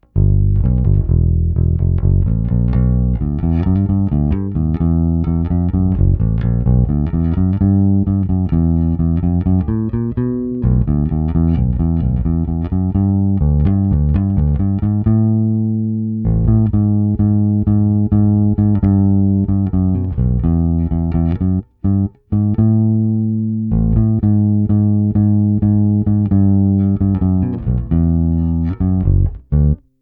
Precision, lehce stažená tónová clona, předzesilovač Fender TBP 1 s korekcemi narovno.
Nahrával jsem to i s puštěným aparátem na "normální" hlasitost, stojící kousek u aparátu, aby byl slyšet rozdíl v otočené fázi.
Bez HPF